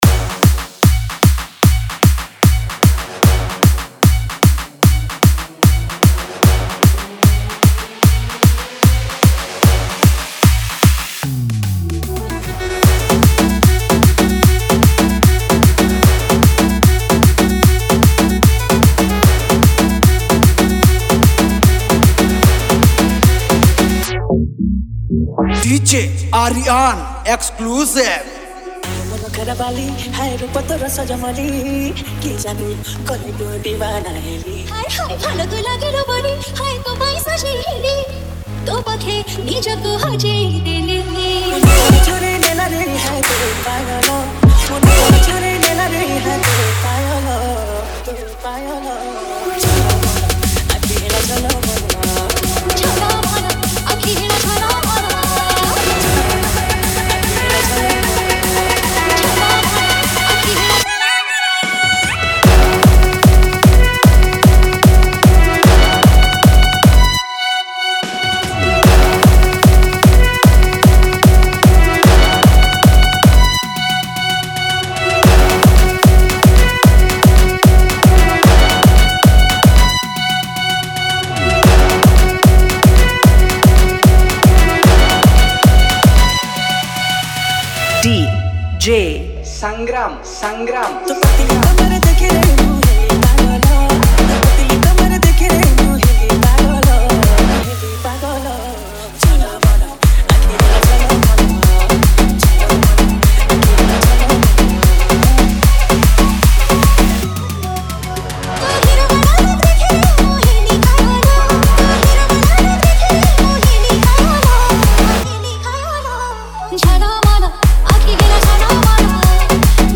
Ganesh Puja Special Dj 2023